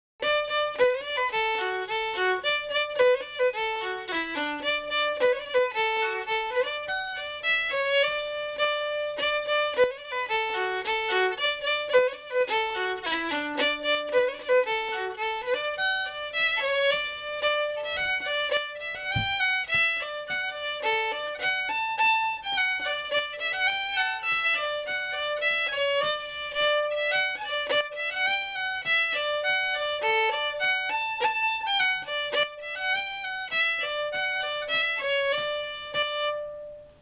Session Tunes